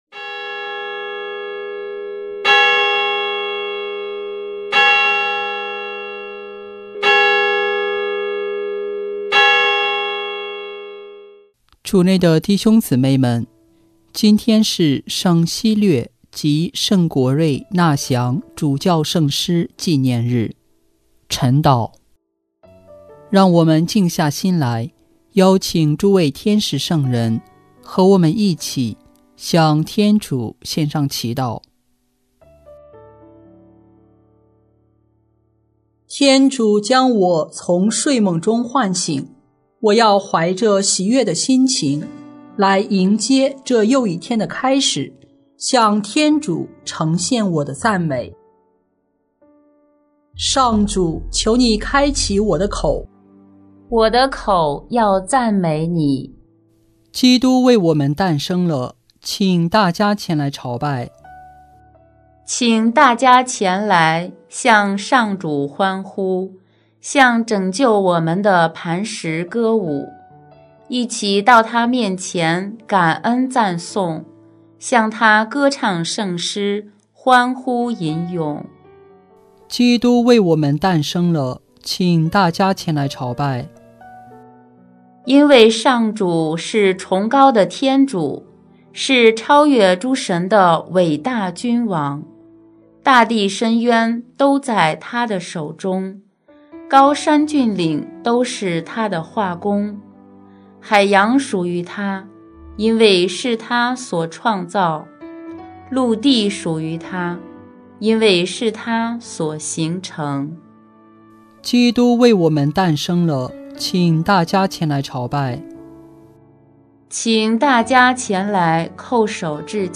【每日礼赞】|1月2日圣巴西略及圣国瑞主教圣师纪念日晨祷 （第一周周五）